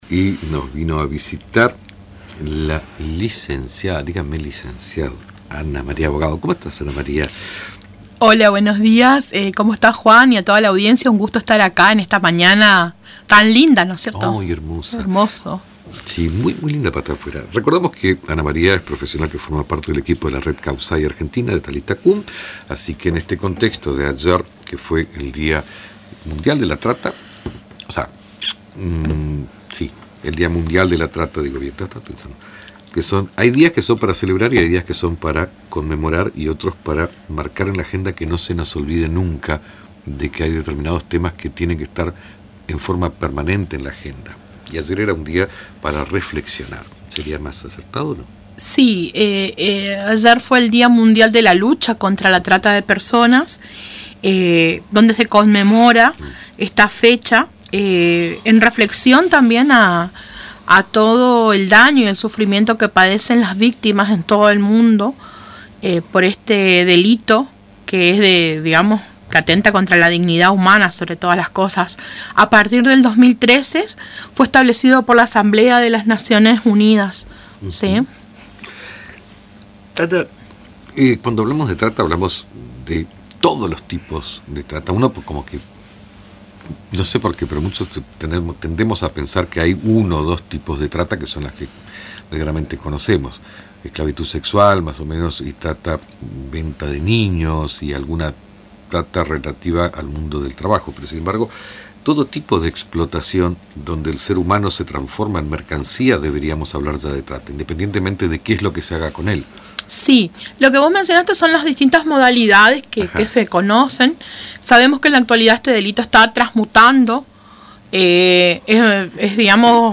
visitó los estudios de Radio Tupambaé para abordar con profundidad este delito que, aunque invisible a los ojos de muchos, afecta a millones de personas en todo el mundo.